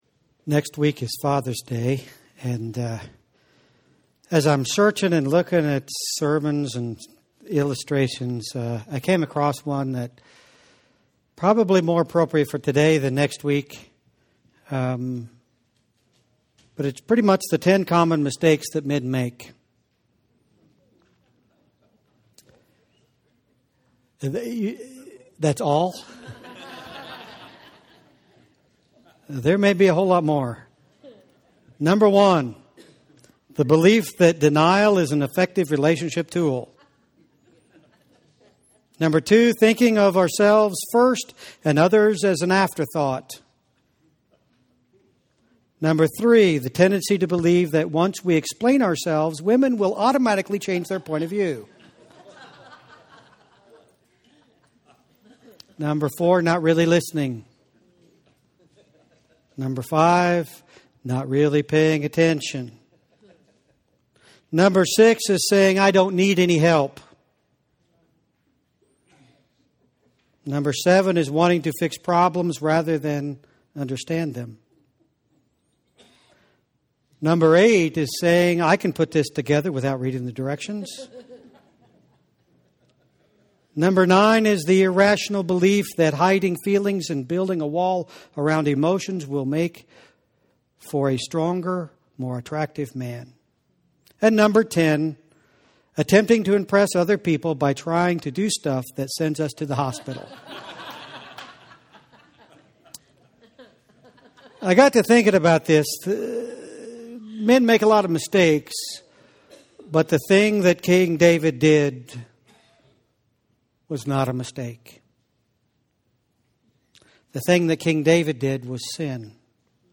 Sunday Sermons - Nishna Valley Christian Church